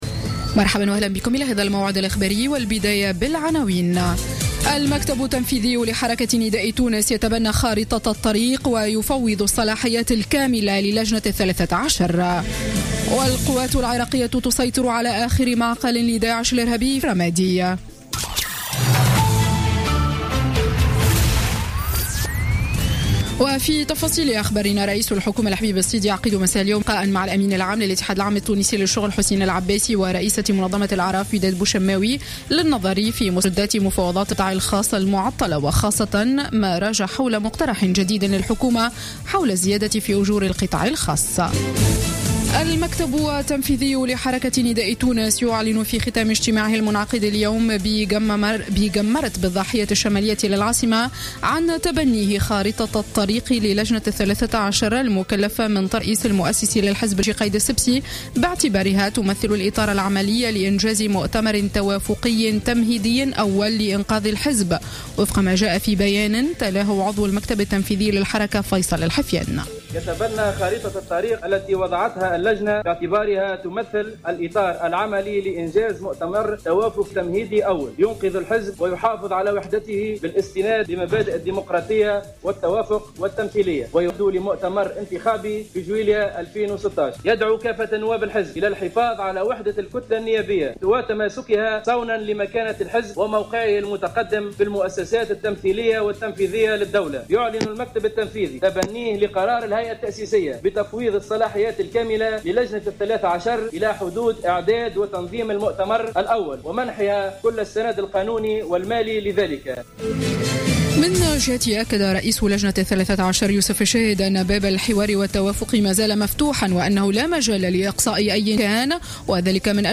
نشرة أخبار السابعة مساء ليوم الأحد 27 ديسمبر 2015